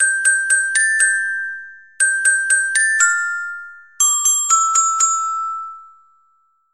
Carillon_Soprano.mp3